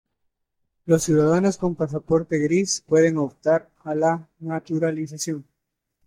Read more to opt Frequency 35k Hyphenated as op‧tar Pronounced as (IPA) /obˈtaɾ/ Etymology Borrowed from Latin optō In summary Borrowed from Latin optāre (“to choose”).